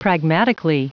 Prononciation du mot pragmatically en anglais (fichier audio)
Prononciation du mot : pragmatically